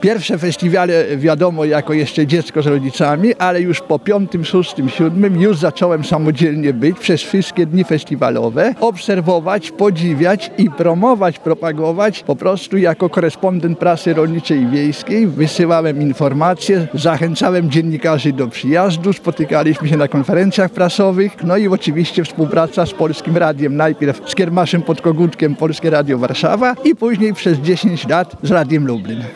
W Kazimierzu Dolnym rozpoczął się trzeci koncert konkursowy 59. Ogólnopolskiego Festiwalu Kapel i Śpiewaków Ludowych. To 30 kolejnych prezentacji – solistów, instrumentalistów, kapel i zespołów śpiewaczych.